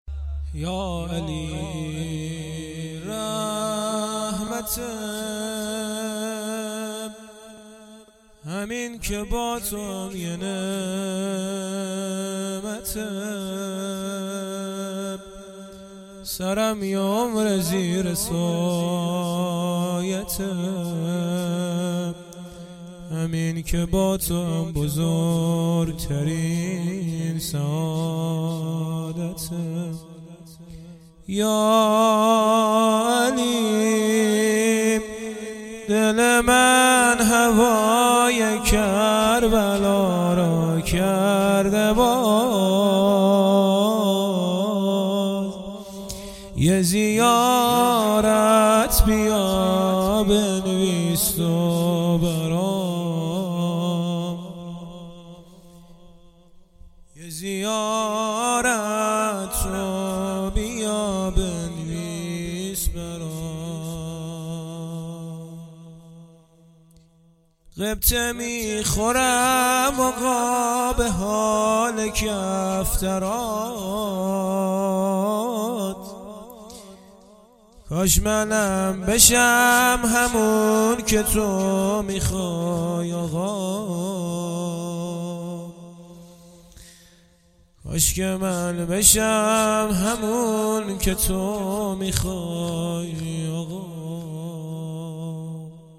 هیئت شیفتگان جوادالائمه علیه السلام مشهد الرضا
ولادت امام علی ۱۳۹۸